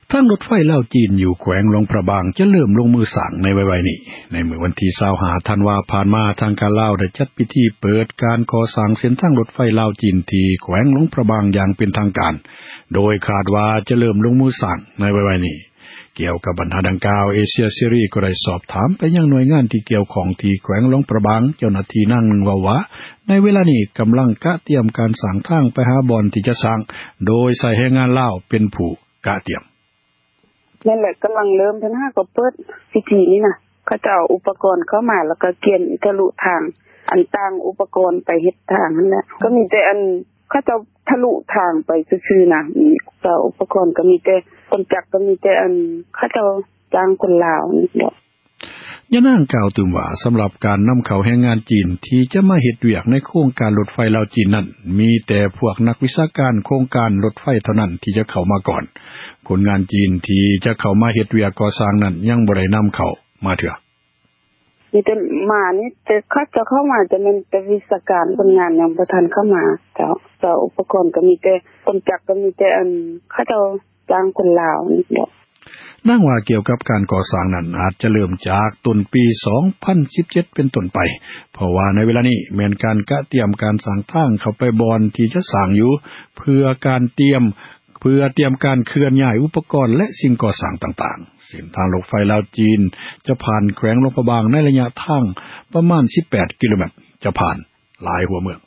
Lao voices